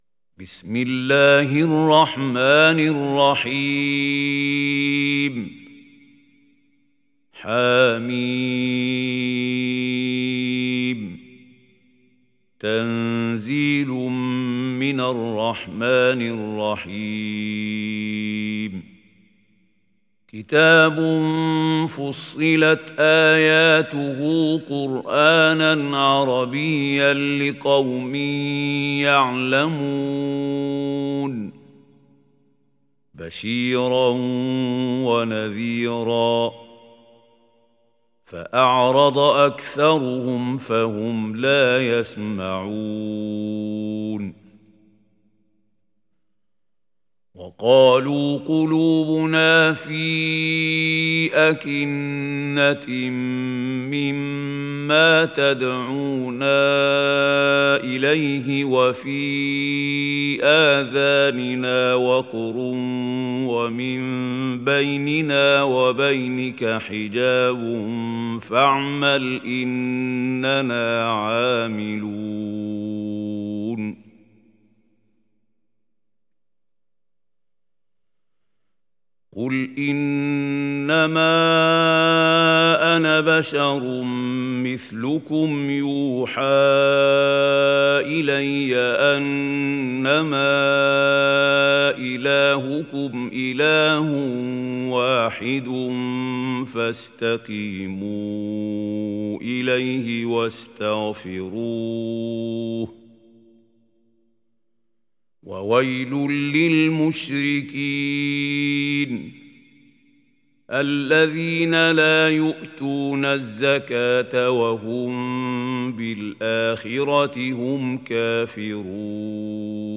সূরা ফুসসিলাত ডাউনলোড mp3 Mahmoud Khalil Al Hussary উপন্যাস Hafs থেকে Asim, ডাউনলোড করুন এবং কুরআন শুনুন mp3 সম্পূর্ণ সরাসরি লিঙ্ক